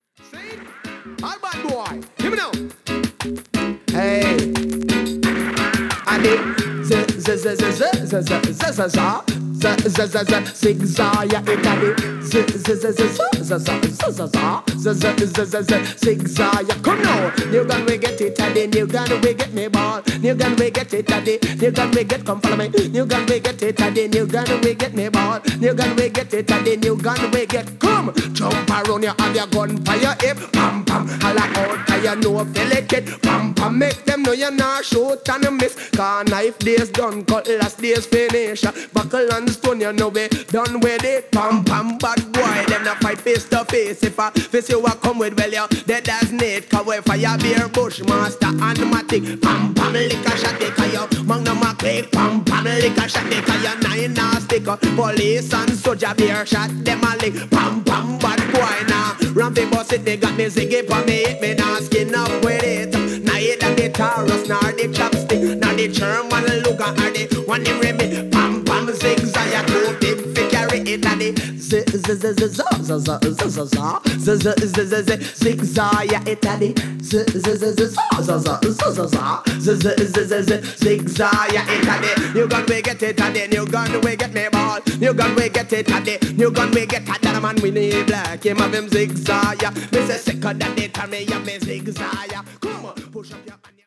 ９０年代・KILLER DIGI CLASSIC!!!